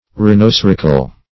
Search Result for " rhinocerical" : The Collaborative International Dictionary of English v.0.48: Rhinocerial \Rhi`no*ce"ri*al\, Rhinocerical \Rhi`no*cer"ic*al\, a. (Zool.)